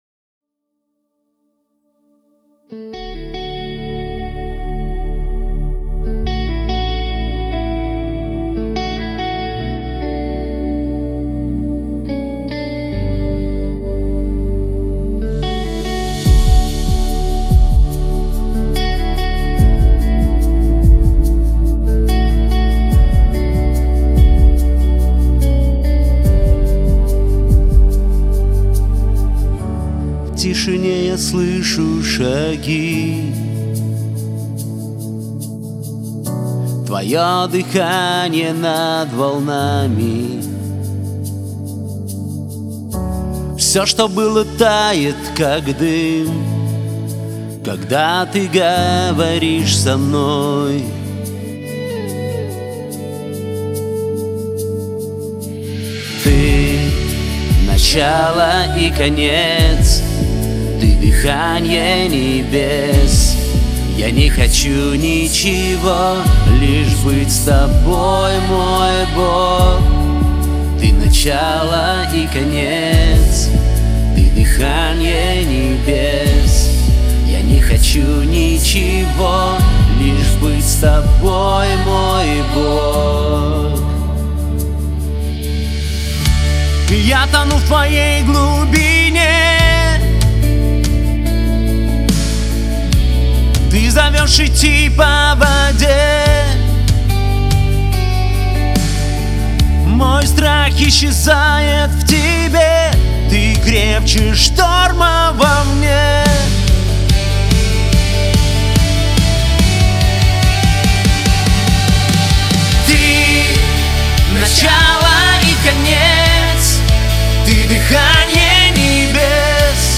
песня
80 просмотров 568 прослушиваний 4 скачивания BPM: 72